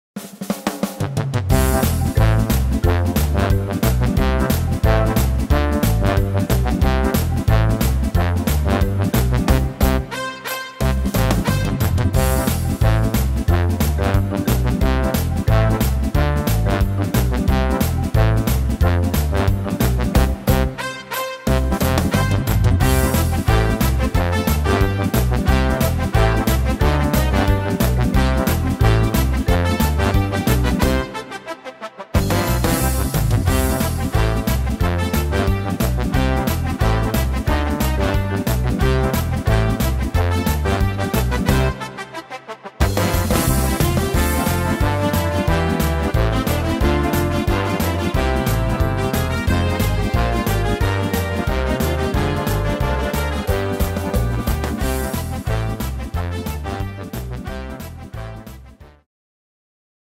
Tempo: 180 / Tonart: C-Dur